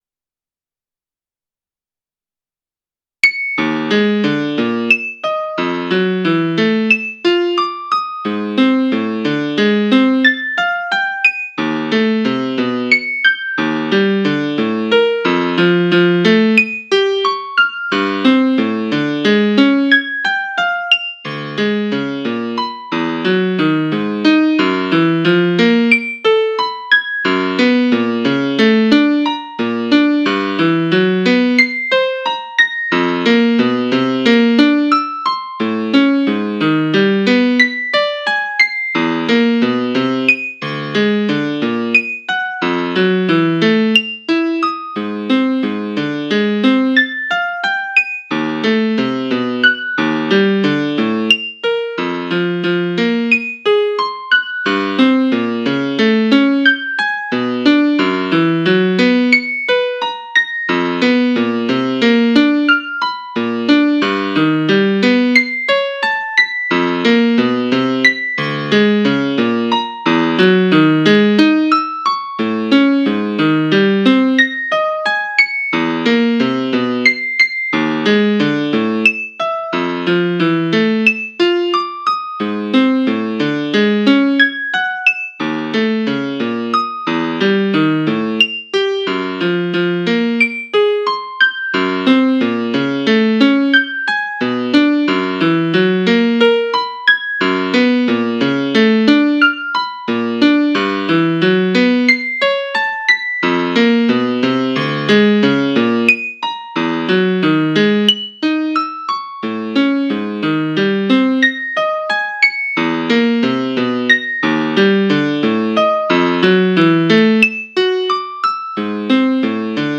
Music 3 Inverted: (Faster pace): Lower pitch represents greater distance between 2 bounces)
1noteper033secinverted.wav